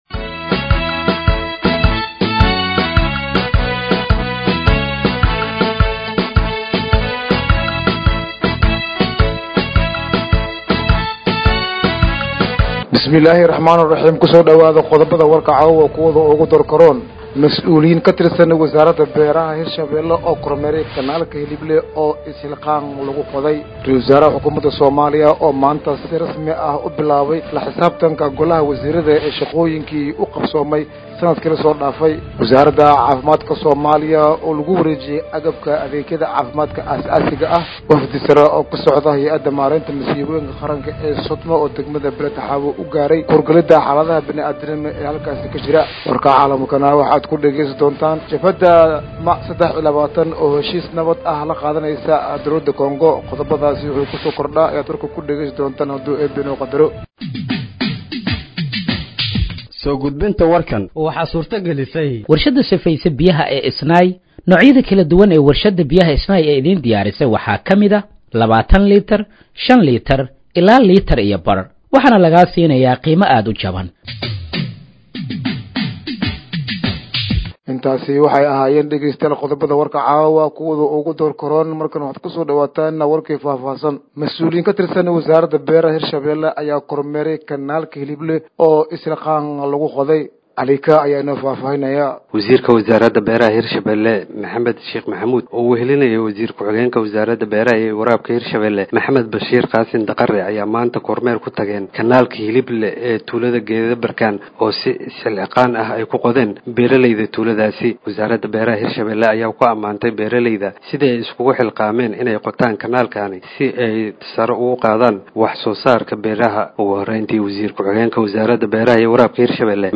Dhageeyso Warka Habeenimo ee Radiojowhar 18/08/2025